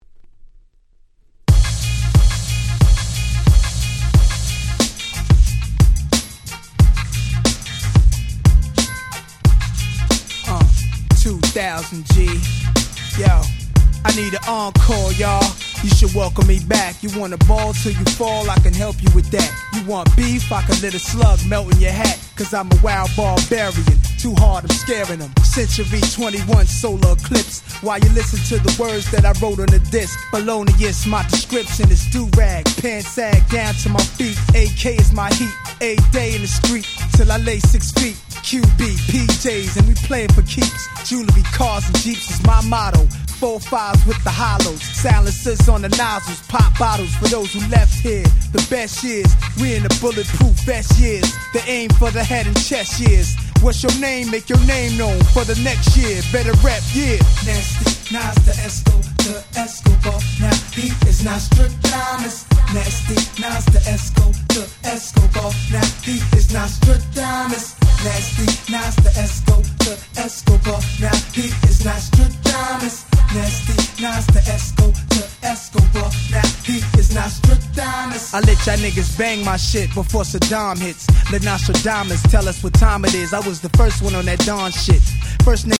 99' Super Hit Hip Hop !!
Boom Bap